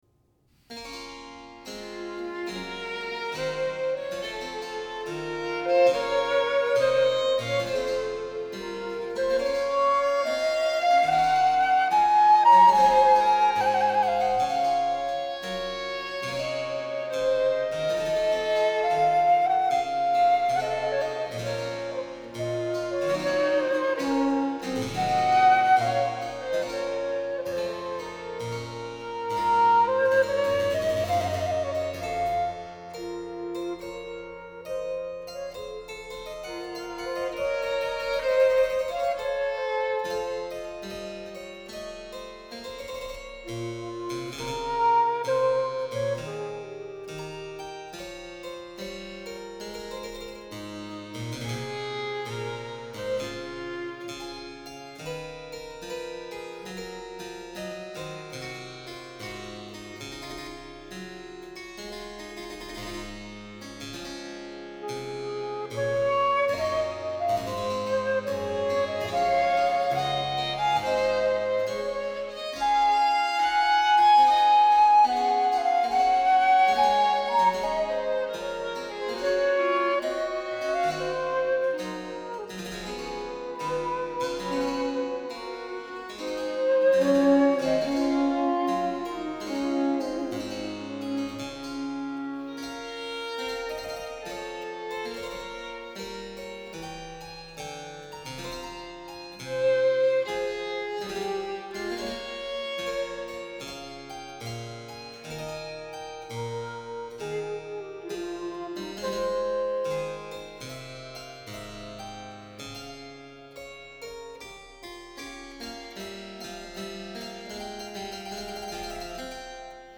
Brandenburg-Concerto-no.-5-in-D-major-Affetuoso.mp3